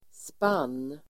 Uttal: [span:]